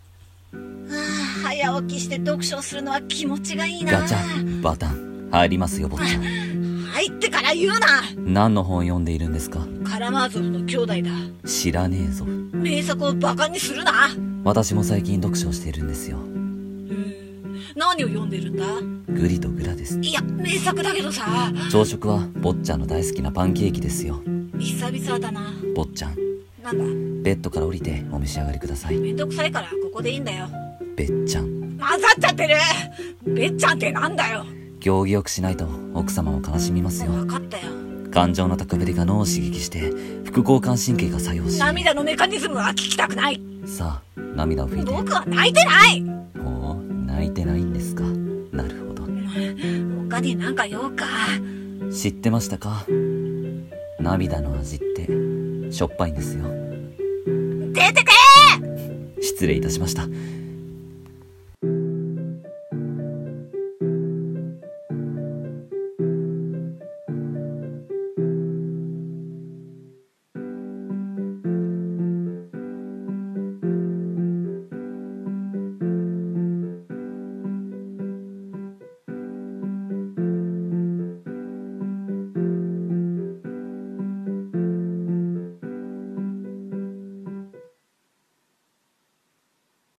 ギャグ声劇「執事とぼっちゃん